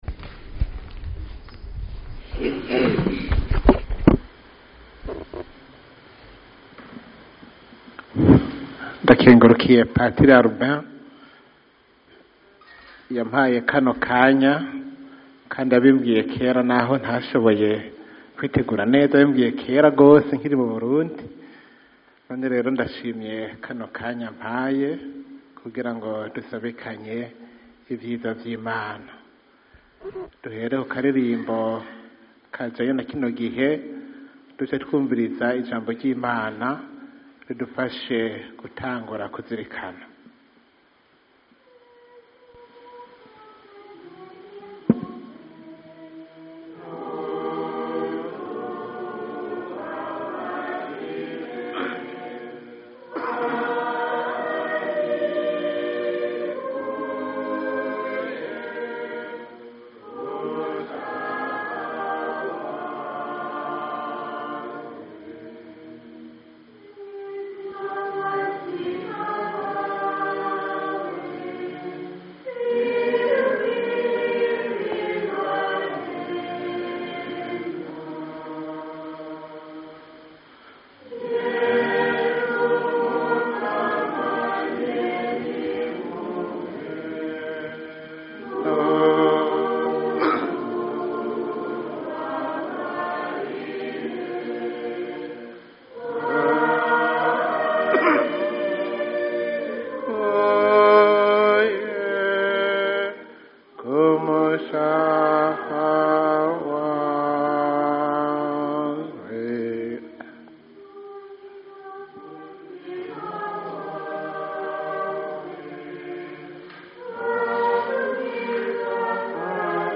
Abasaserdoti, Abihebey’Imana n’abari muri iyo nzira barangurira ubutumwa bwabo i Roma no mu micungararo yaho baragize Umwiherero w’Ikarema ubategurira guhimbaza Pasika y’2017.
Ngi’iyi inyigisho yatanze.